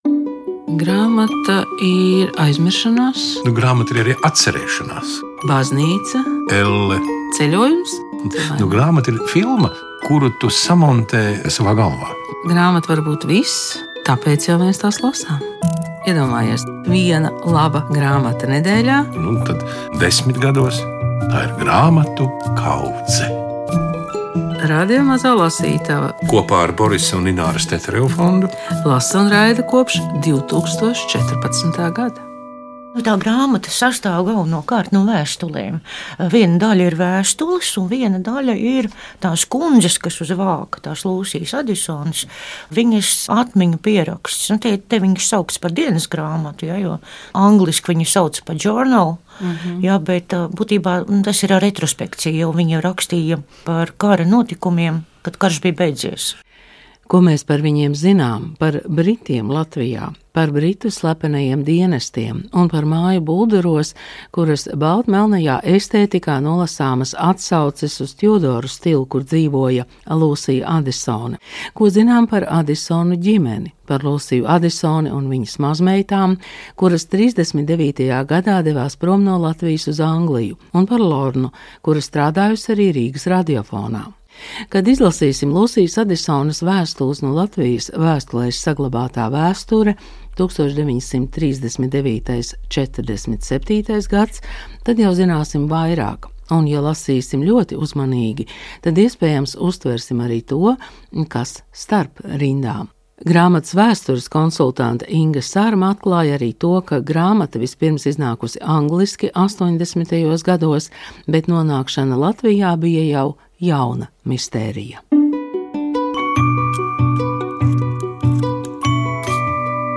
saruna ar grāmatu autoriem, tulkotājiem un redaktoriem, kuri neformālā gaisotnē atbild uz viņu jautājumiem.